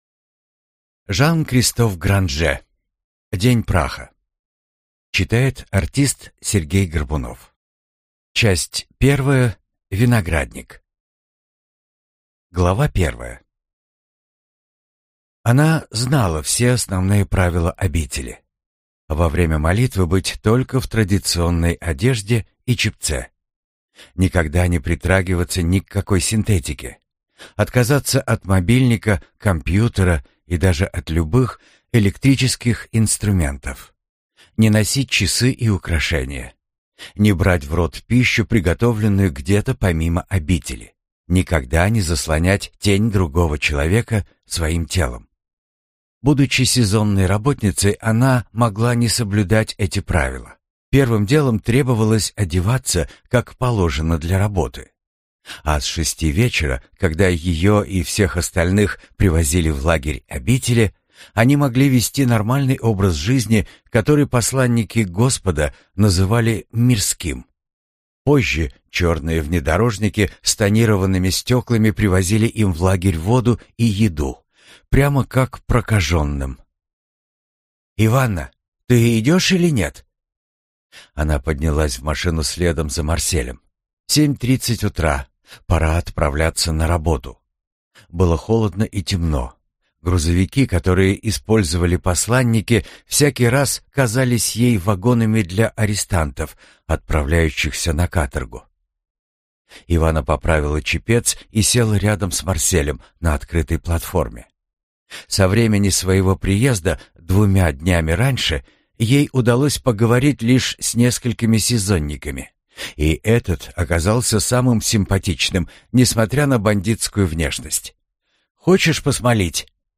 Аудиокнига День Праха - купить, скачать и слушать онлайн | КнигоПоиск